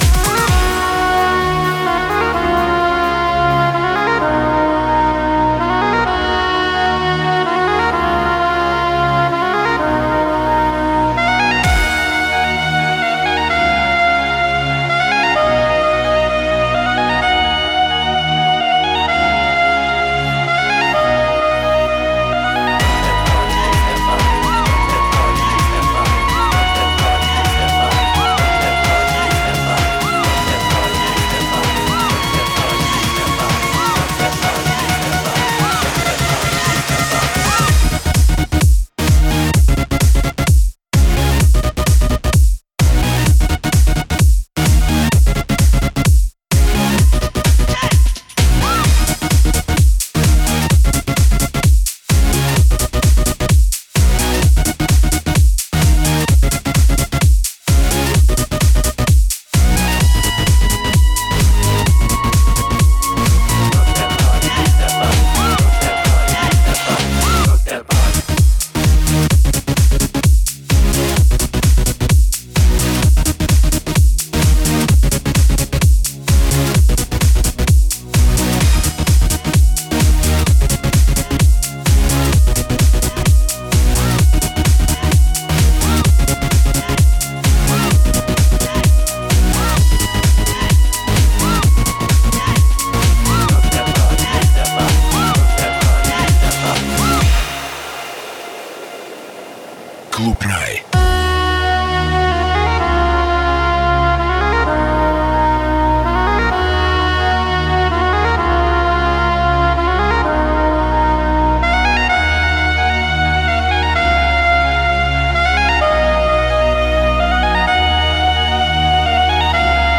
Хорошая клубная музыка
Horoshaya-klubnaya-muzyka.mp3